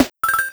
Sound effect of Kill in Super Mario World: Super Mario Advance 2